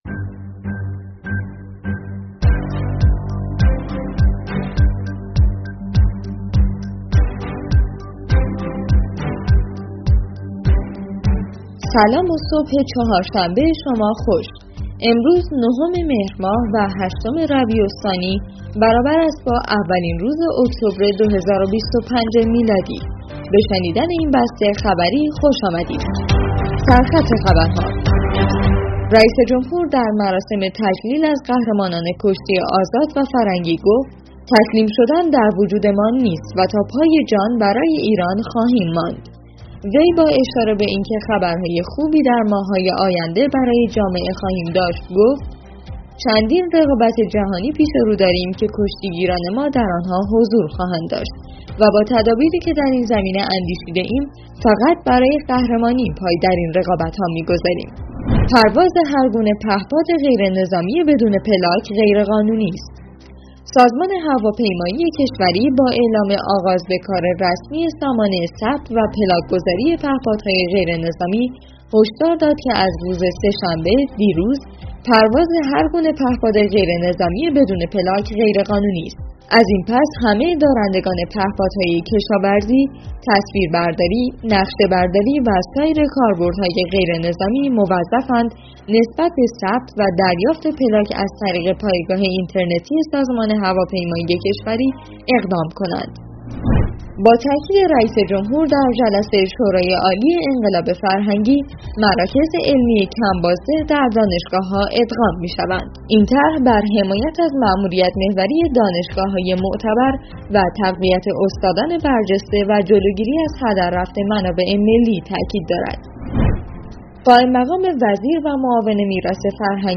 بسته خبری_تحلیلی ایرناصدا نهم مهرماه